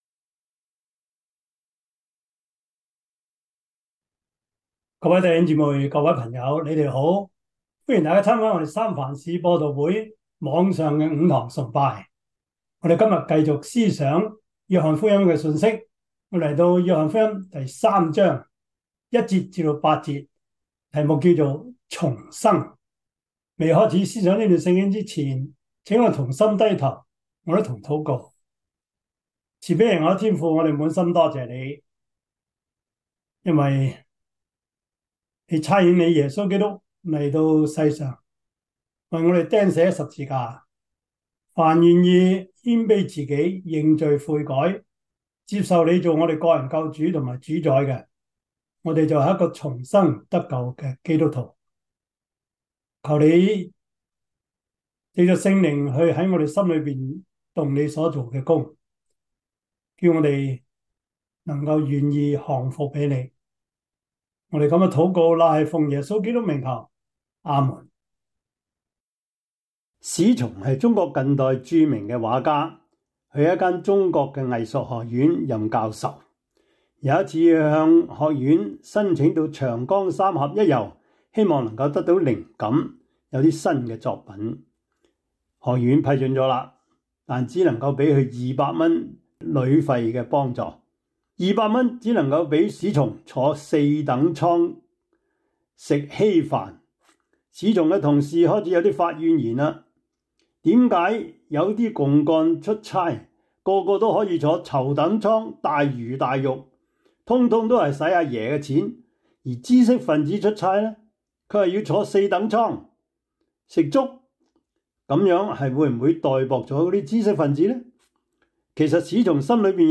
約翰福音 3:1-8 Service Type: 主日崇拜 約翰福音 3:1-8 Chinese Union Version